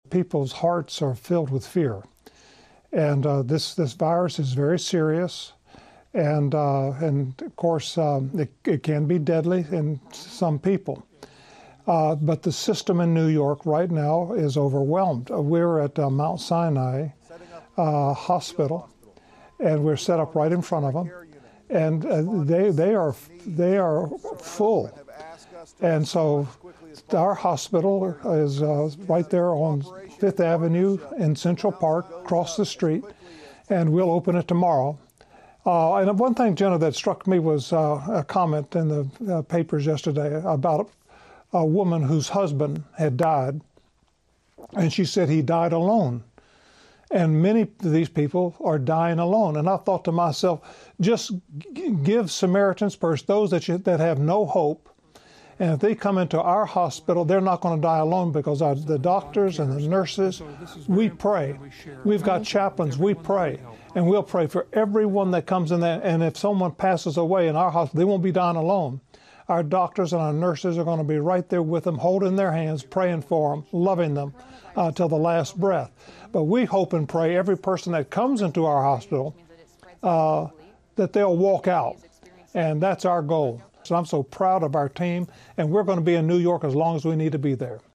Rev. Graham gave it on a CBN newscast